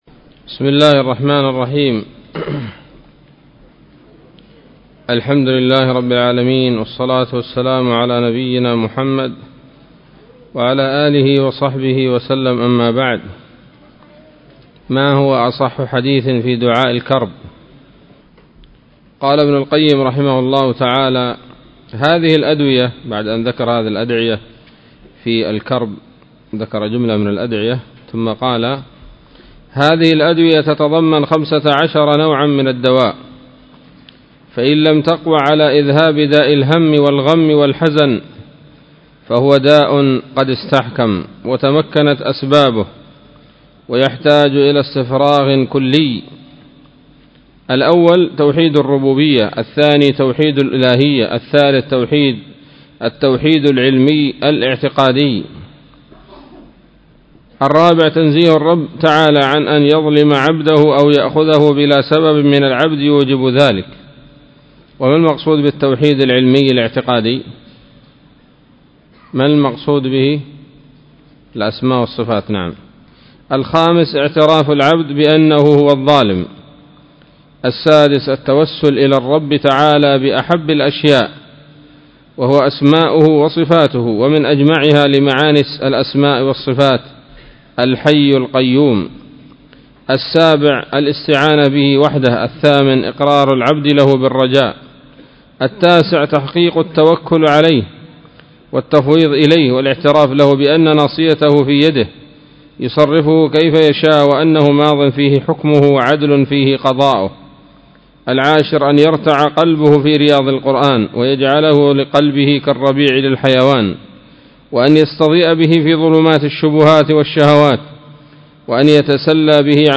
الدرس السادس والخمسون من كتاب الطب النبوي لابن القيم